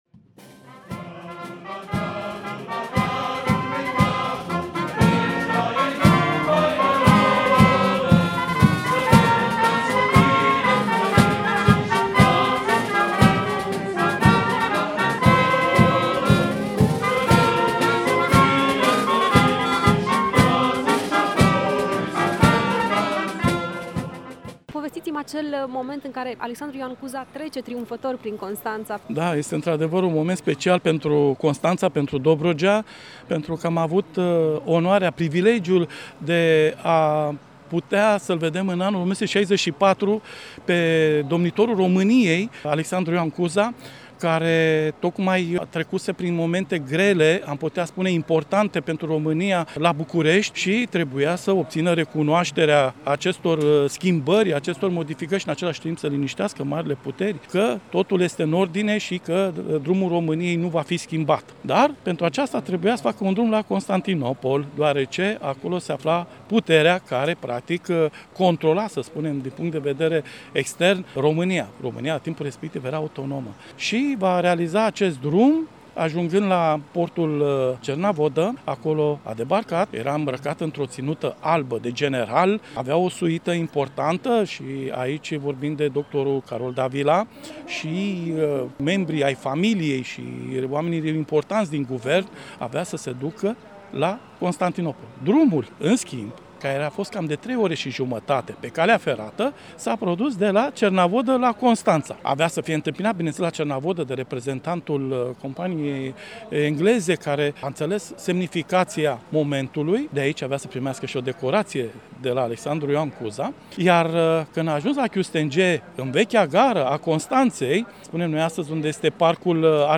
Pe o ploaie măruntă și în miros de mare, constănțenii au sărbătorit, astăzi, în Piața Ovidiu, cei 166 de ani care au trecut de la Unirea Principatele Române – Moldova cu Țara Românească.
Urmează un reportaj